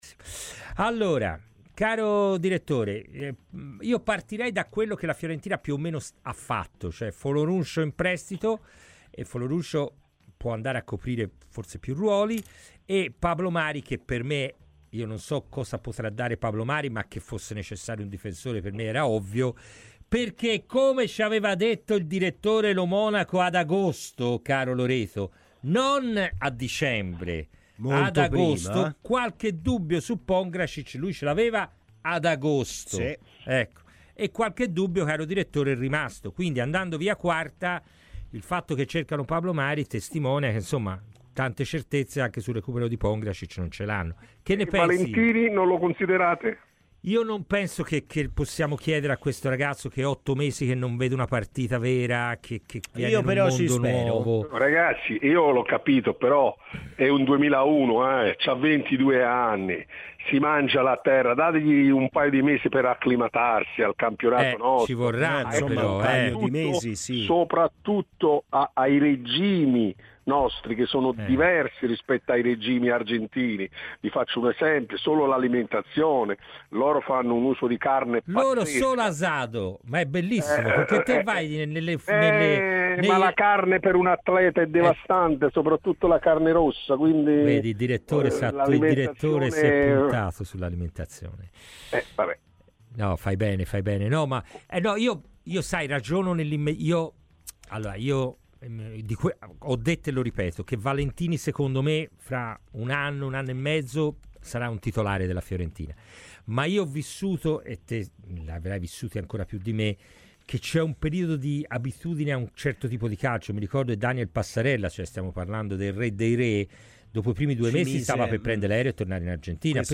ai microfoni di Radio FirenzeViola durante 'Palla al centro'